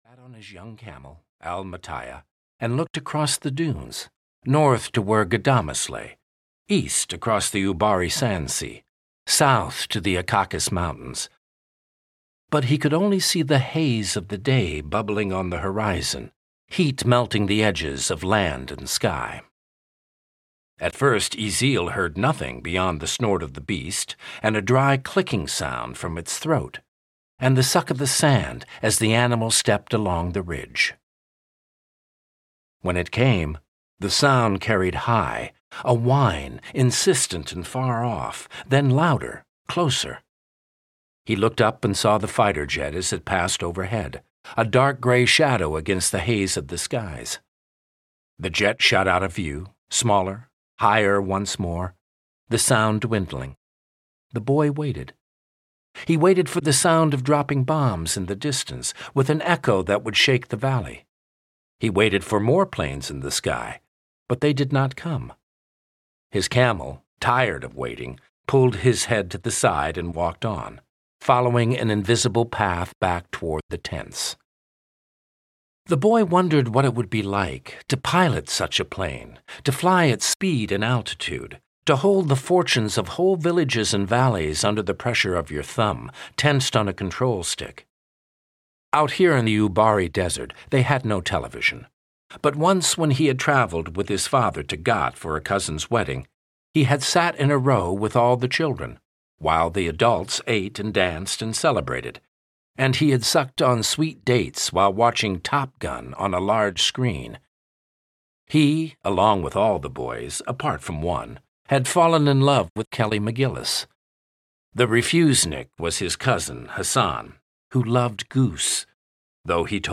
Lenny (EN) audiokniha
Ukázka z knihy